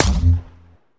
hit2.ogg